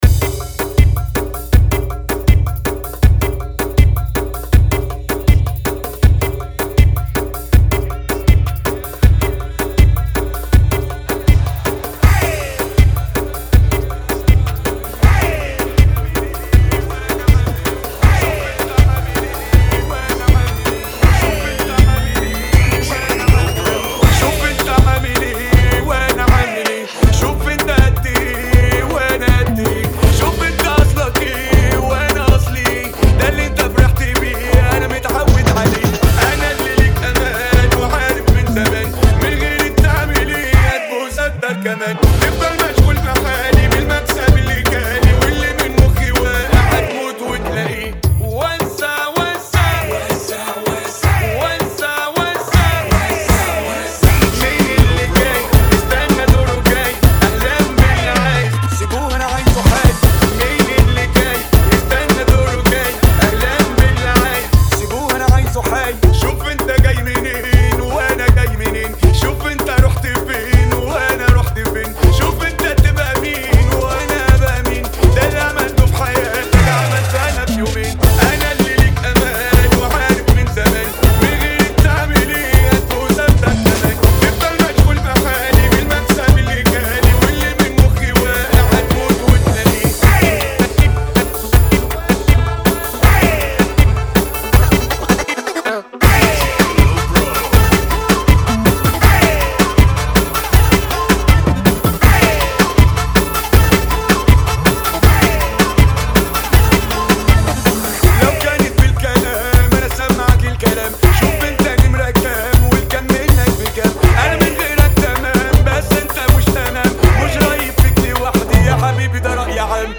[ 80 Bpm ]